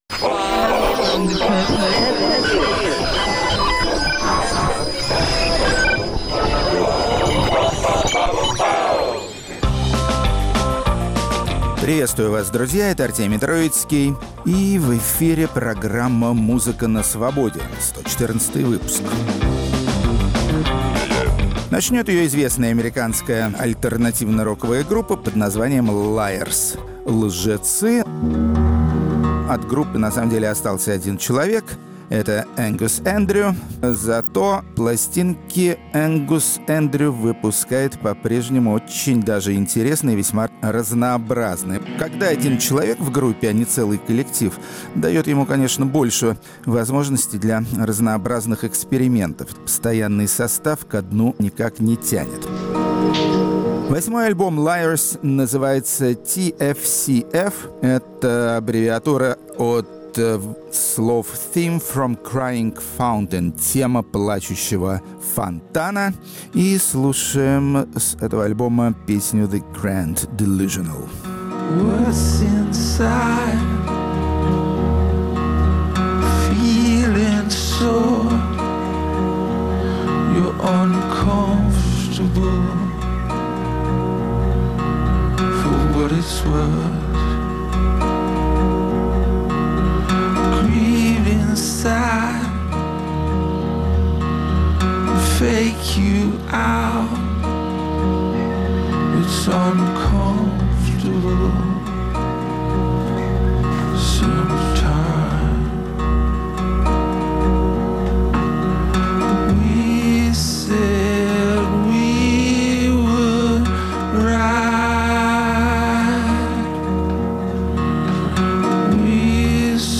Есть и такой, способствовавший популярности музыки тёмного джаза, подруги меланхолии, ипохондрии и плохого настроения. Рок-критик Артемий Троицкий отдаёт должное немецким новаторам, помещая название этой группы в тот де абзац, в котором упоминается сам Майлз Дэвис.